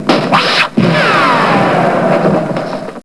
vlaserfire.wav